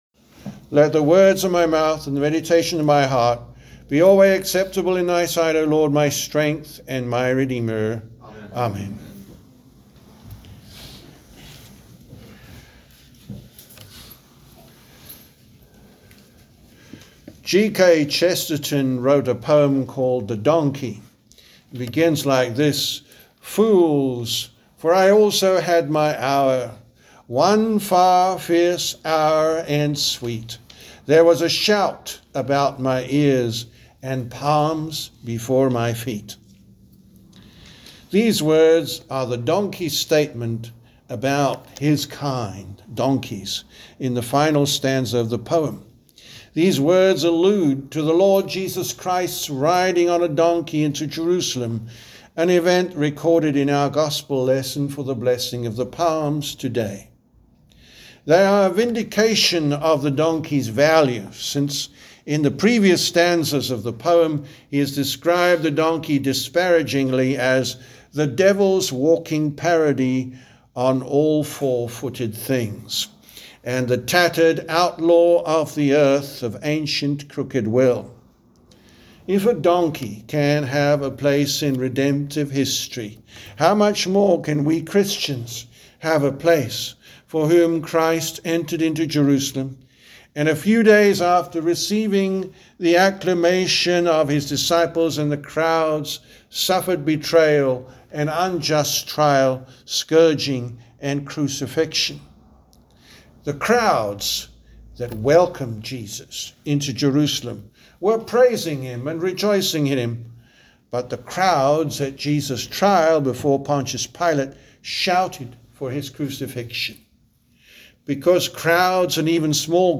The Sermon for Palm Sunday, March 29th, 2026